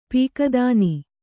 ♪ pīka dāni